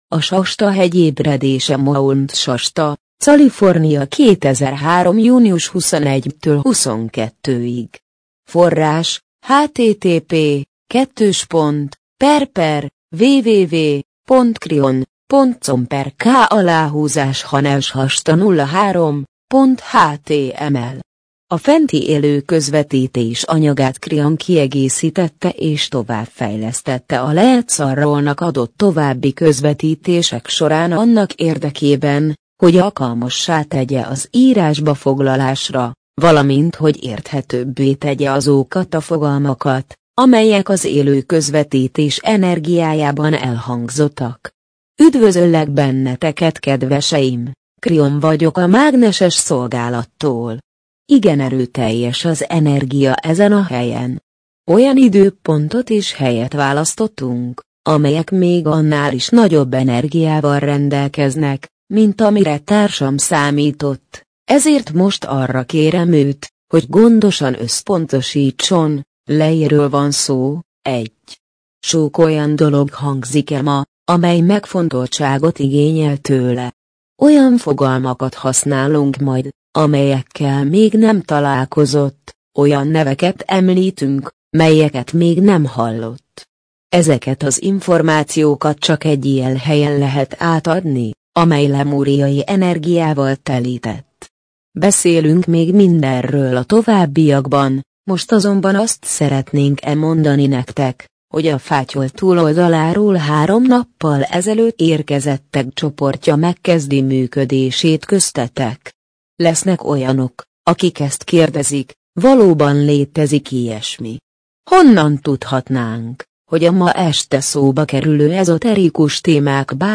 MP3 gépi felolvasás A Shasta-hegy ébredése A Shasta-hegy ébredése Mount Shasta, California - 2003. június 21-22.